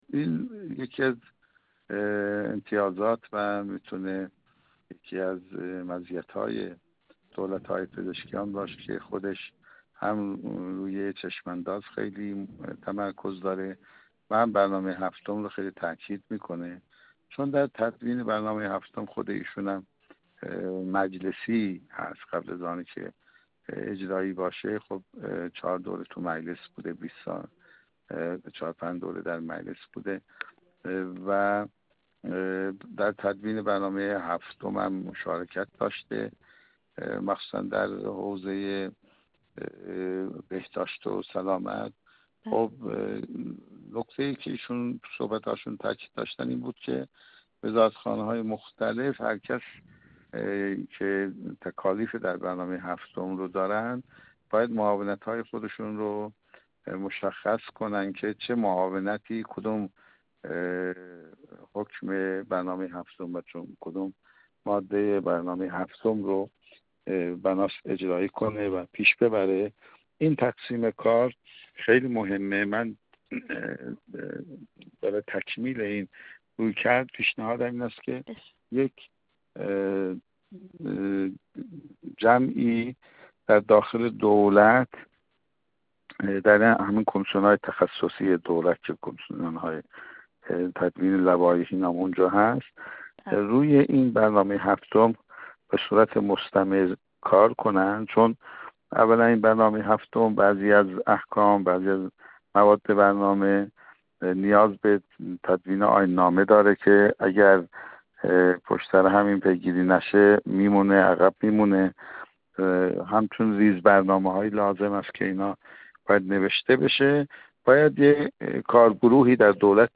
حجت‌الاسلام والمسلمین سیدمحمدرضا میرتاج‌الدینی، عضو سابق کمیسیون برنامه و بودجه مجلس شورای اسلامی
گفت‌و گو